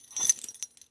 foley_keys_belt_metal_jingle_03.wav